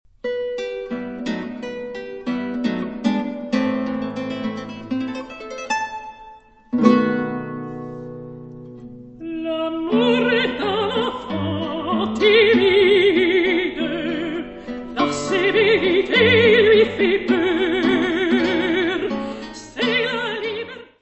mezzo-soprano
guitar.
Music Category/Genre:  Classical Music